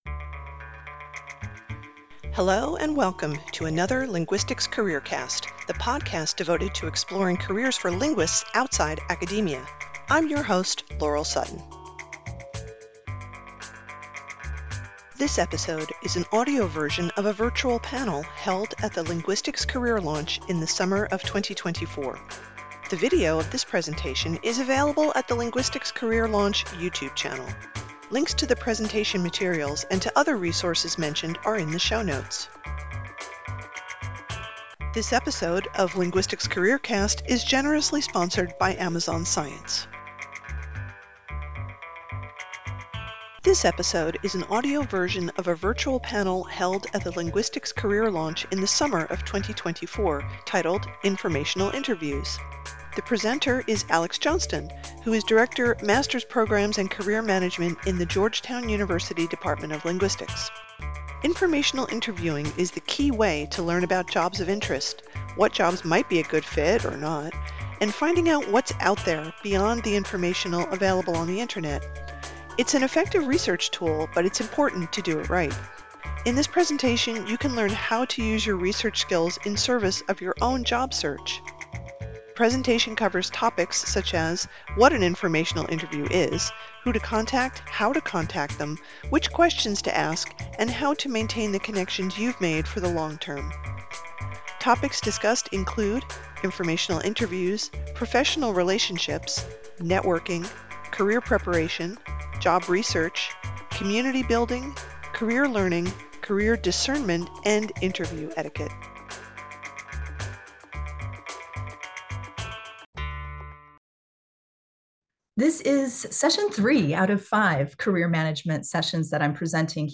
This episode is an audio version of a virtual panel held at the Linguistics Career Launch in the summer of 2024, titled “Informational Interviews”.